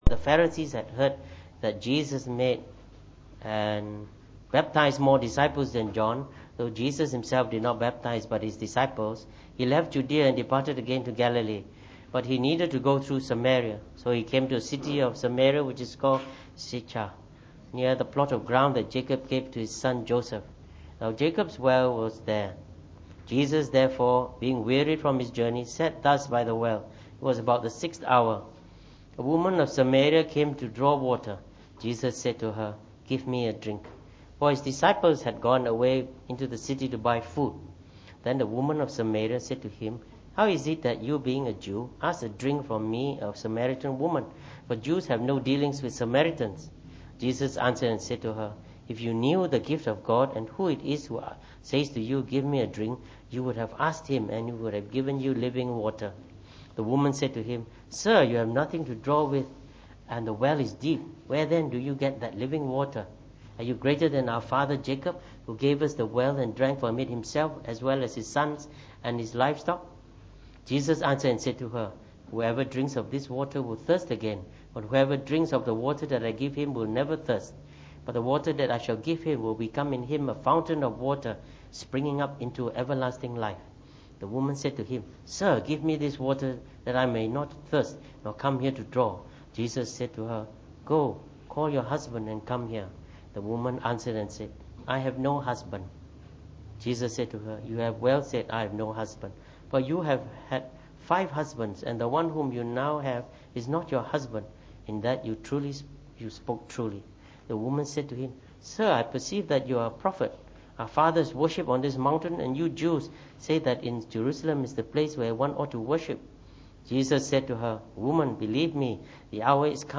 during the Evening Service